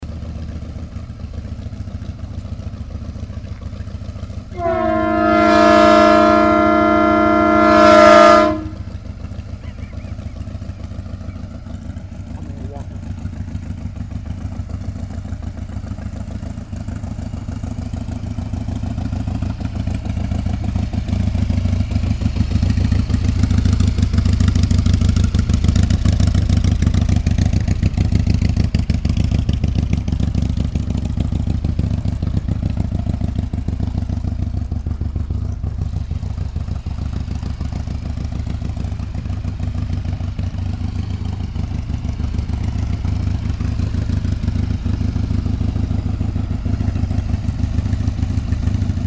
horn.